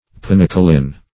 Search Result for " pinacolin" : The Collaborative International Dictionary of English v.0.48: Pinacolin \Pi*nac"o*lin\, n. [Pinacone + L. oleum oil.]